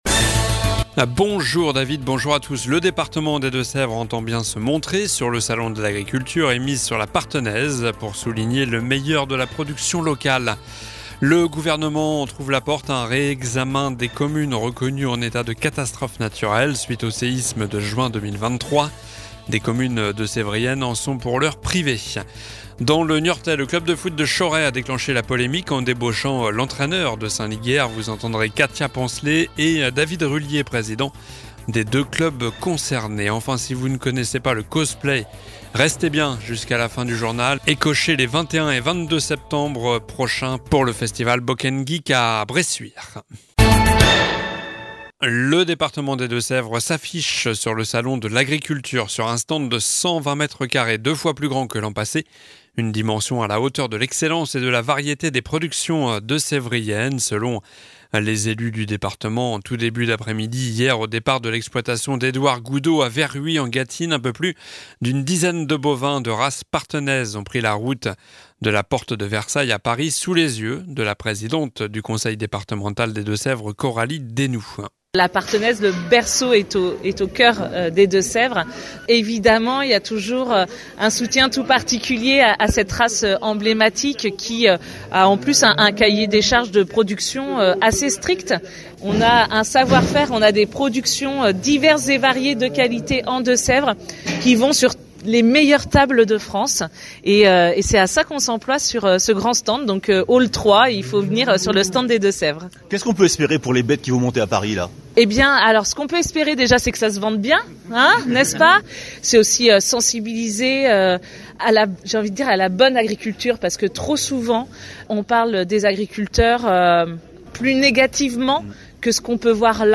Jouurnal du mercredi 28 février (midi)